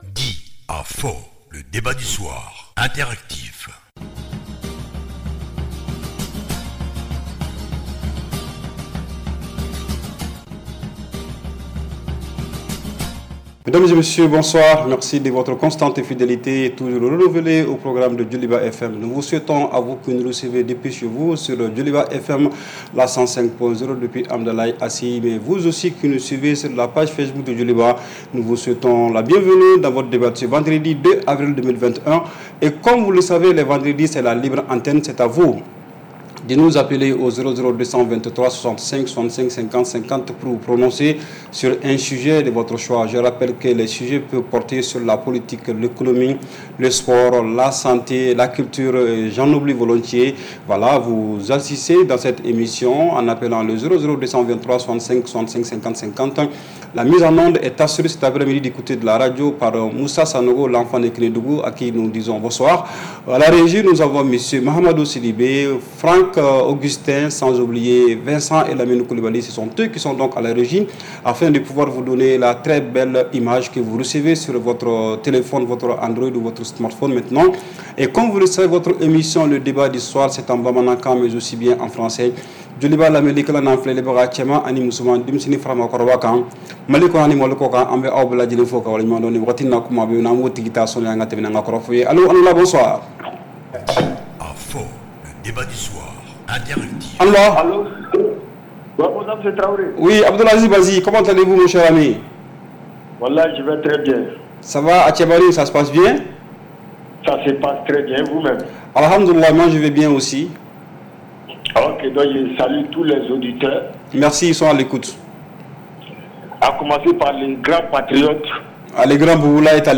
REPLAY 02/04 – « DIS ! » Le Débat Interactif du Soir